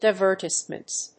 音節di・ver・tisse・ment 発音記号・読み方
/dɪvˈɚːṭɪsmənt(米国英語)/